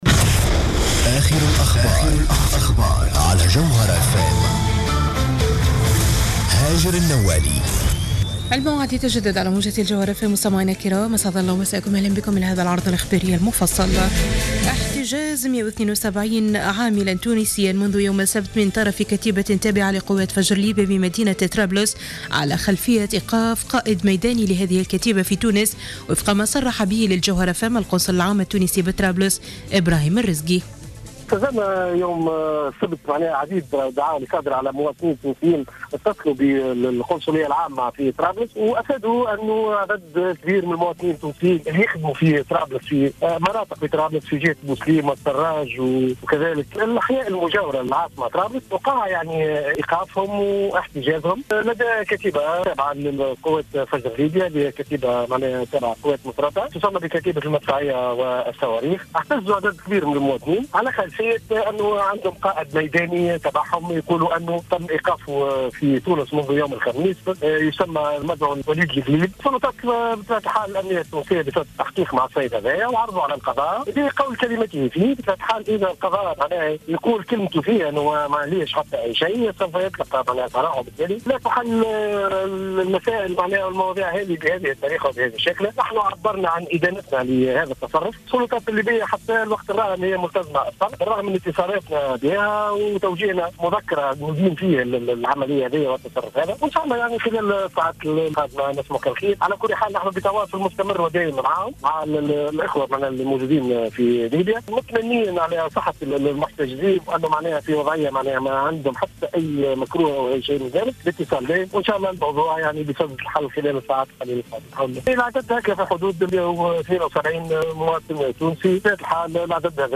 نشرة أخبار منتصف الليل ليوم الإثنين 18 ماي 2015